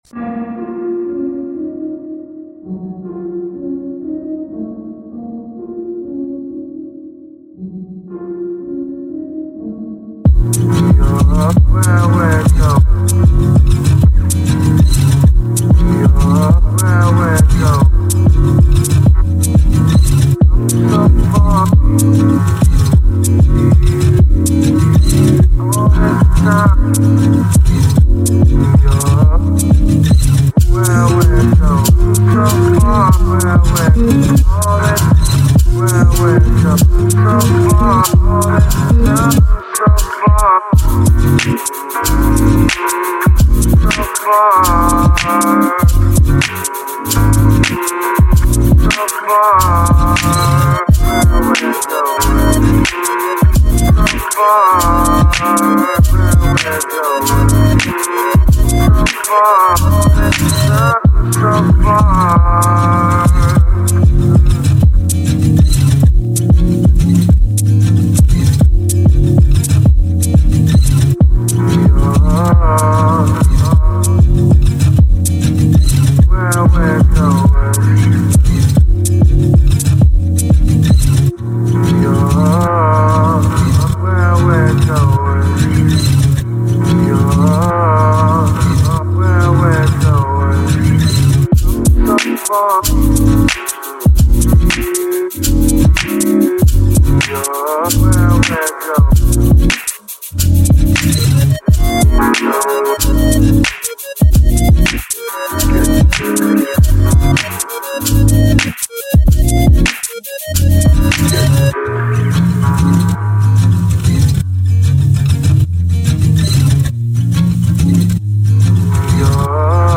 Electro/Soul laced beats